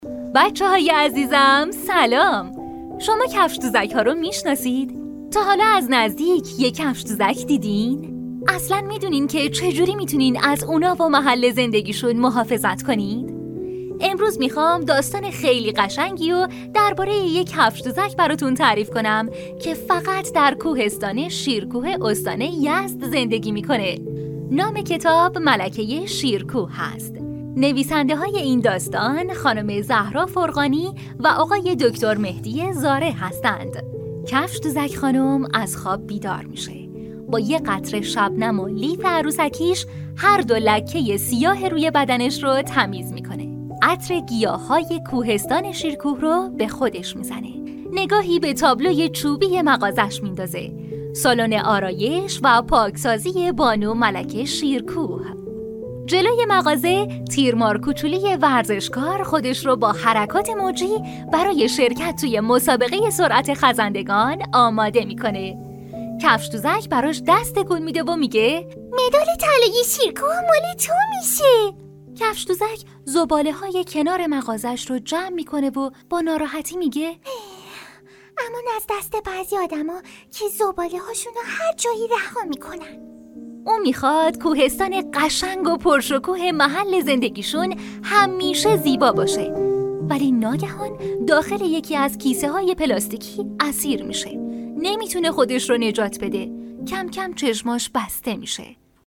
Female
Young
Adult
Kids-Audio-Book